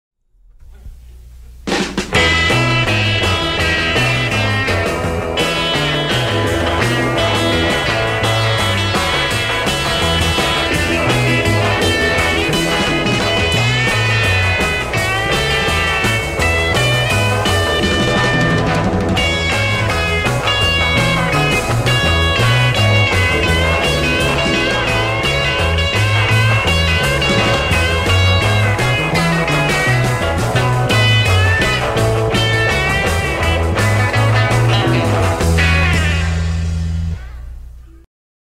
композицию исполняет сессионная группа